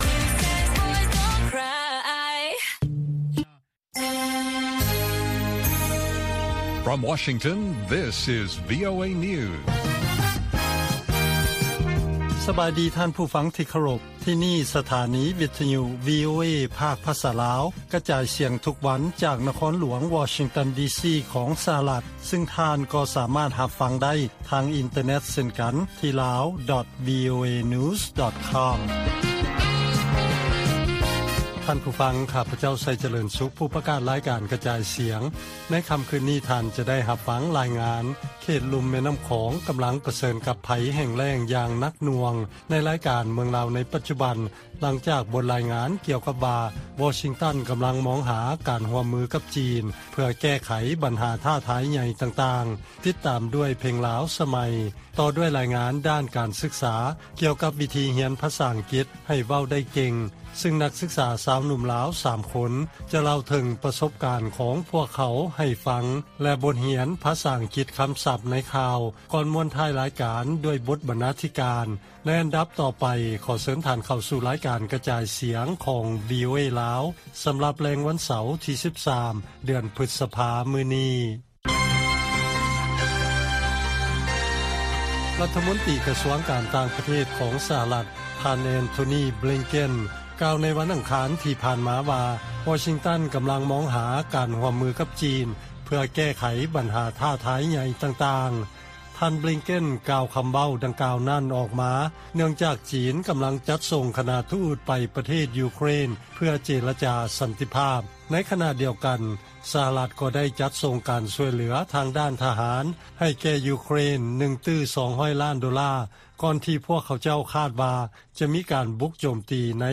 ລາຍການກະຈາຍສຽງຂອງວີໂອເອລາວ: ລາຍງານກ່ຽວກັບ ວໍຊິງຕັນກຳລັງມອງຫາການຮ່ວມມືກັບຈີນ ເພື່ອແກ້ໄຂບັນຫາທ້າທາຍໃຫຍ່ຕ່າງໆ